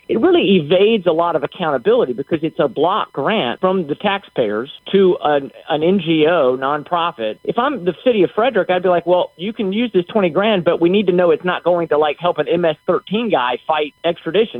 Delegate Justin Ready tells WBAL the money should not be put up by the city and that tracking the spending on grants will be tricky…